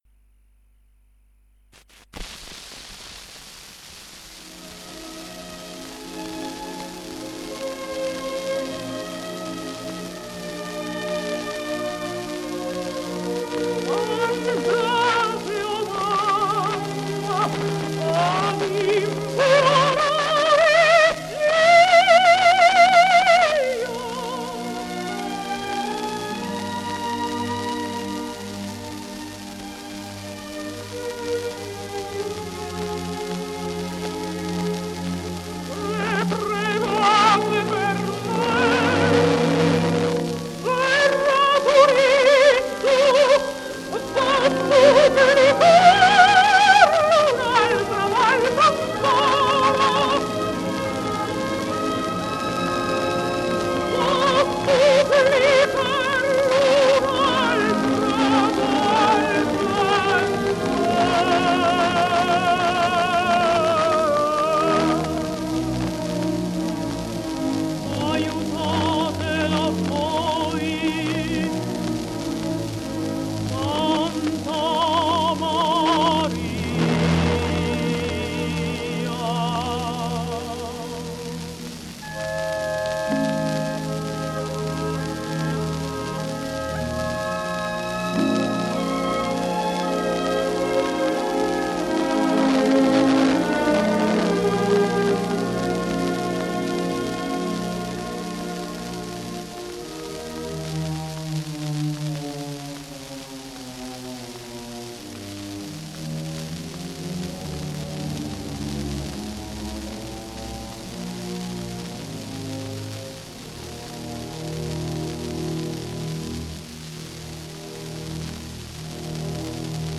undici dischi a 78 giri del 1943 in cui è registrata la Cavalleria Rusticana
La registrazione è avvenuta a Milano sotto l’egida della celeberrima casa discografica LA VOCE DEL PADRONE.
Orchestra e Coro del Teatro alla Scala.
N 11.- Andate o mamma – Scena 4.a – 5.a Bruna Rasa, s. Simionato, contr Ten. Beniamino Gigli         SCARICA